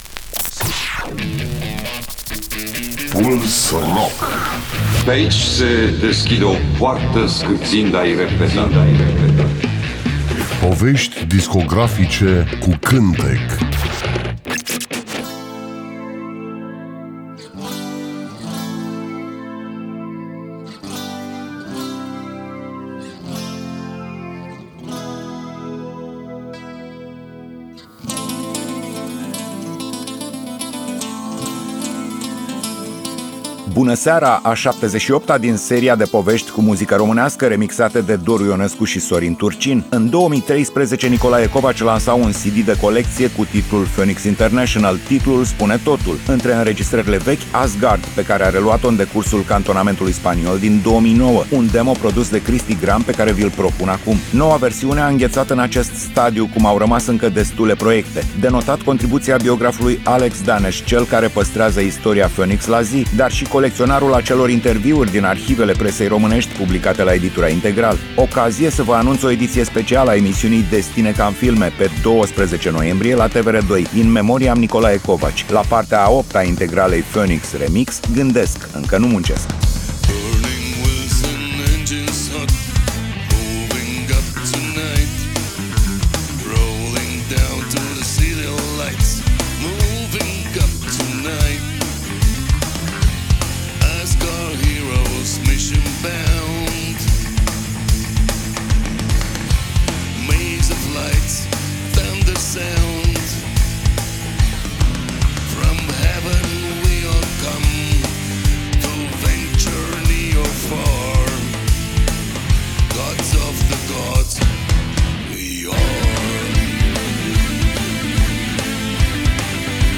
Emisiunea se numește Puls Rock și jonglează cu artiștii noștri rock, folk, uneori chiar jazz.